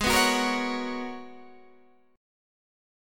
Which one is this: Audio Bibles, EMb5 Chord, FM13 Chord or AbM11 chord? AbM11 chord